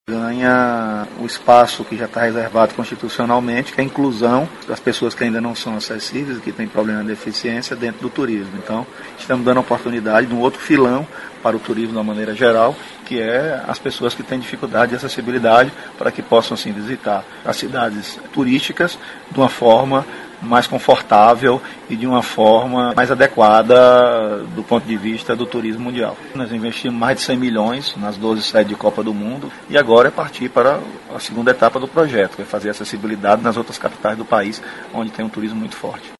aqui para ouvir declaração do secretário Fábio Mota sobre a importância da iniciativa.